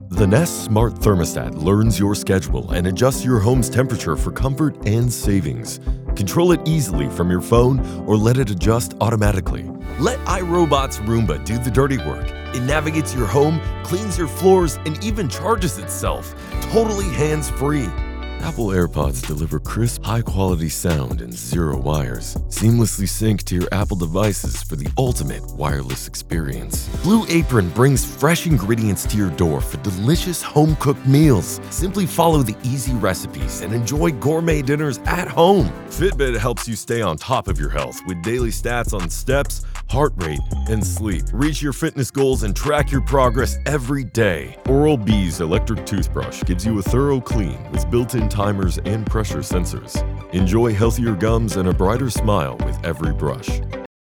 YMCA Canada Voice Over Commercial Actor + Voice Over Jobs